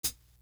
Ray Hat 2.wav